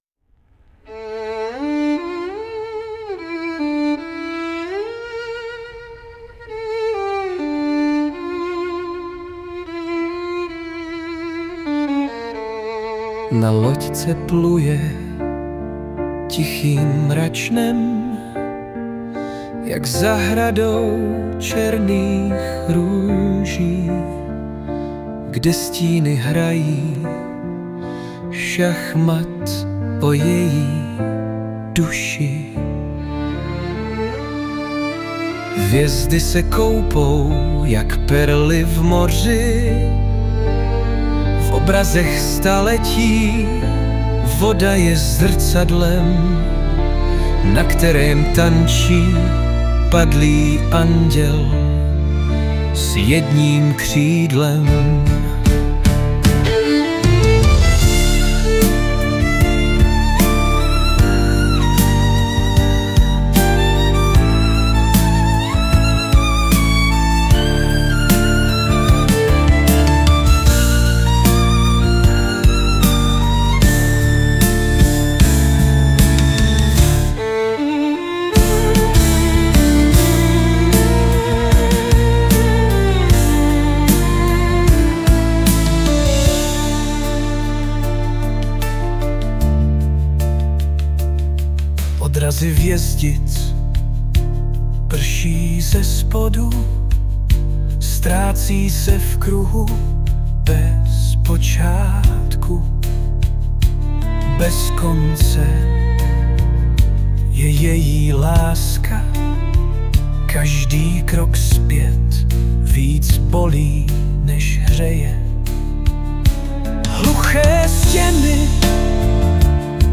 2025 & Hudba, zpěv a obrázek: AI
a bez čtení poslouchám ty smyčce a textu slova